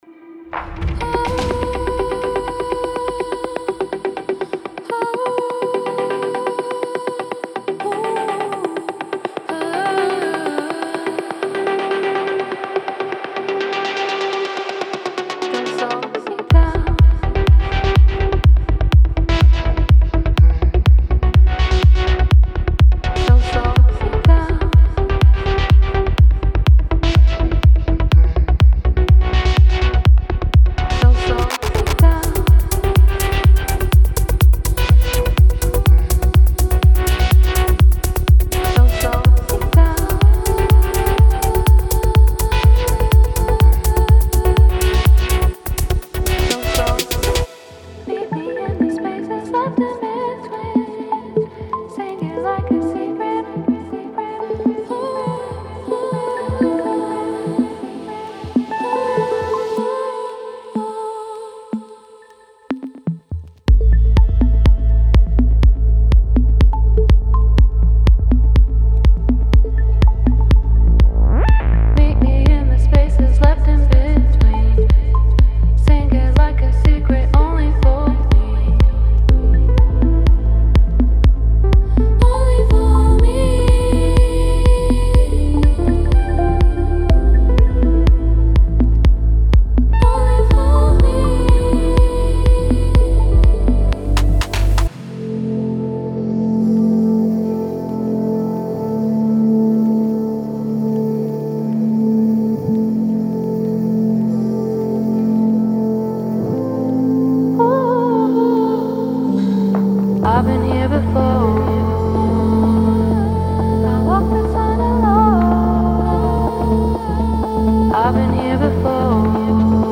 ハウス系おすすめサンプルパック！
デモサウンドはコチラ↓
Genre:House